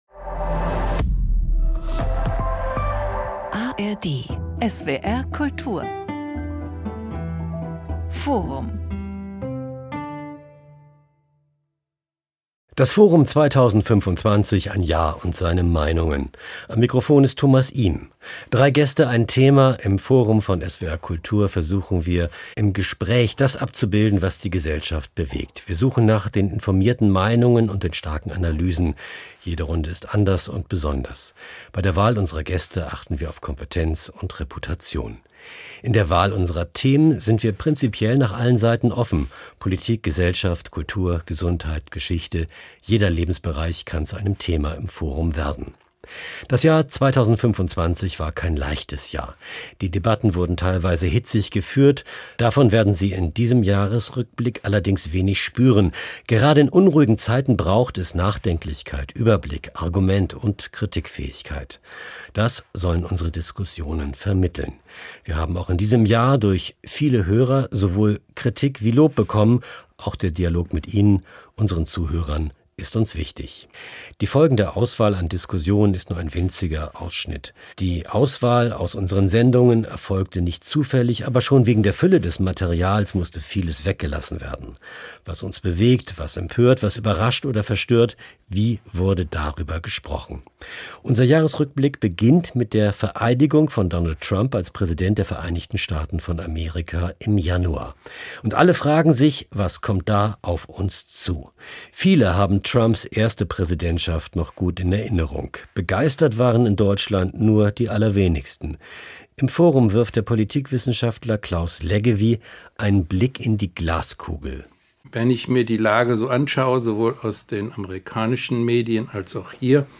Drei Gäste, ein Thema. Im Forum von SWR-Kultur versuchen wir im Gespräch das abzubilden, was die Gesellschaft bewegt. Das Jahr 2025 war kein leichtes Jahr.